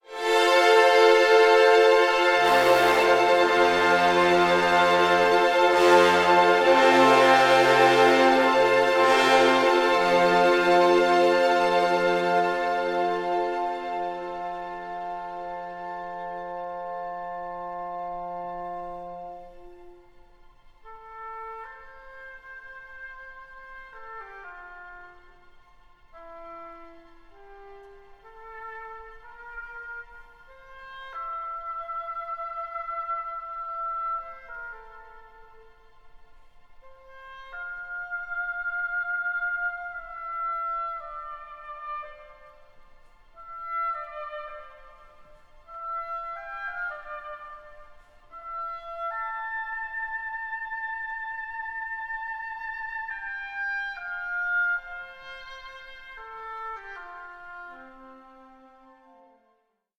tone poem
it’s a bold, optimistic and passionate work